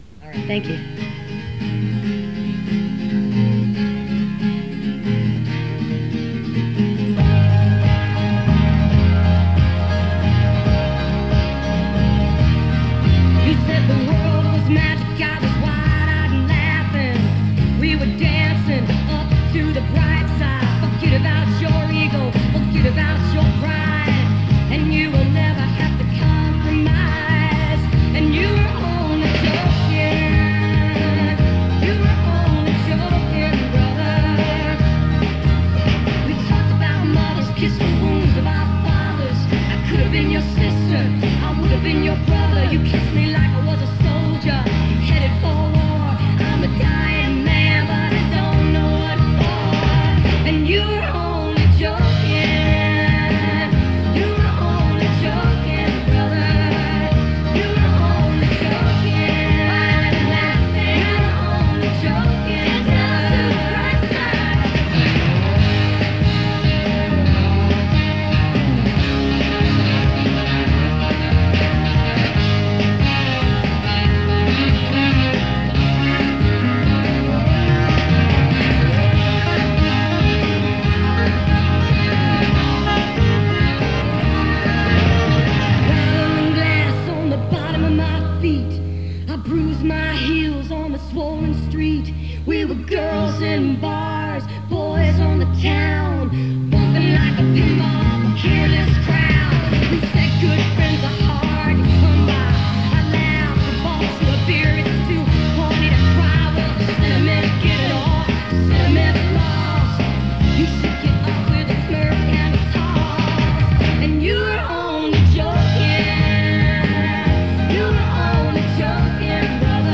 (acoustic duo)
album version